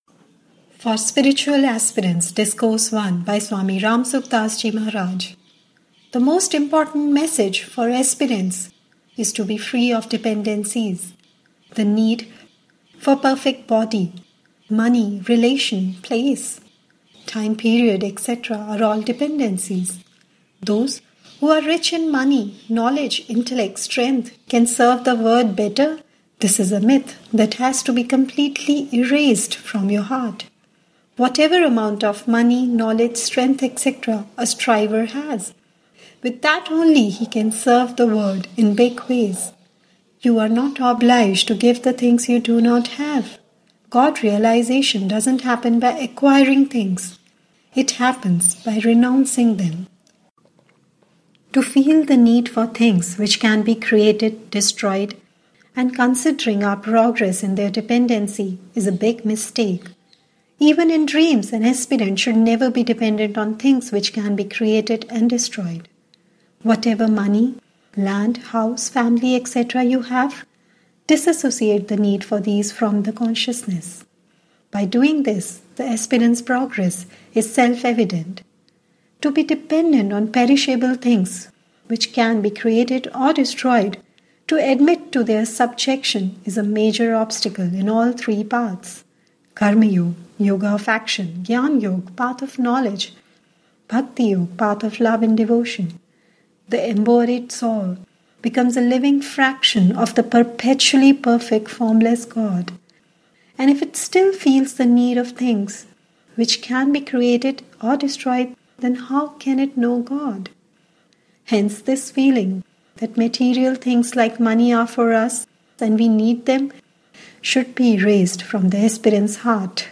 For-Spiritual-Aspirants-Discourse-01.mp3